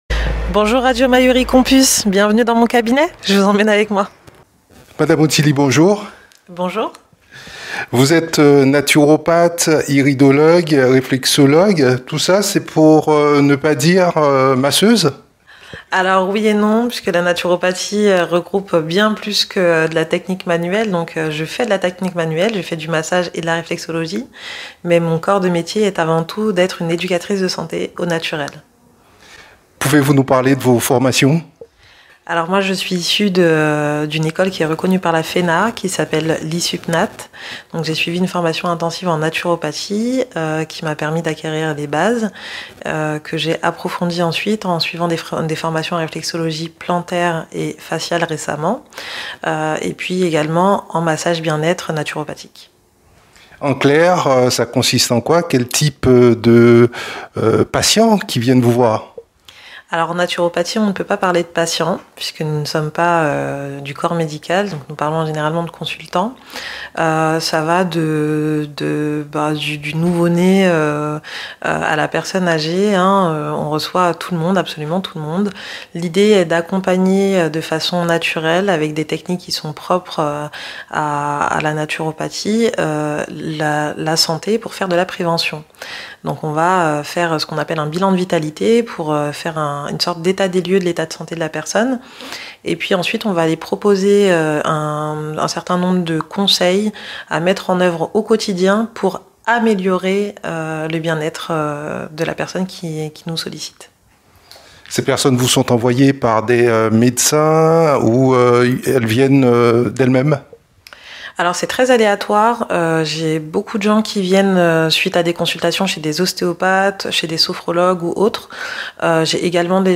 Suivez notre entretien avec elle en podcast et en vidéo.